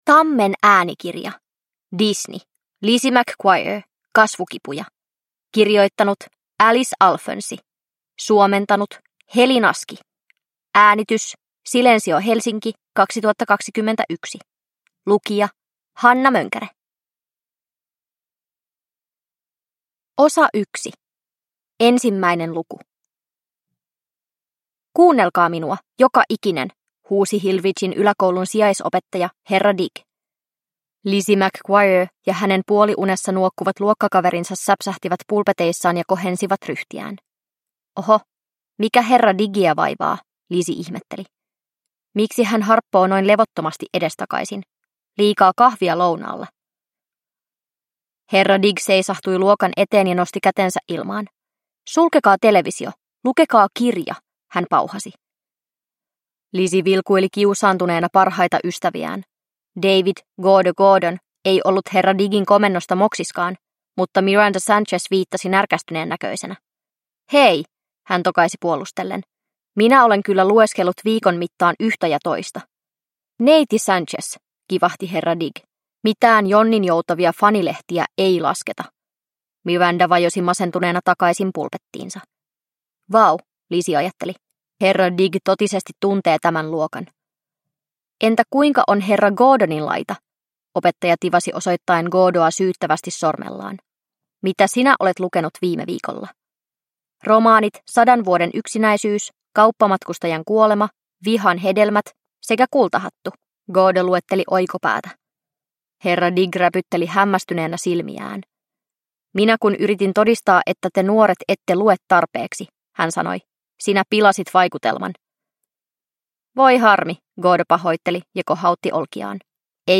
Lizzie McGuire. Kasvukipuja – Ljudbok – Laddas ner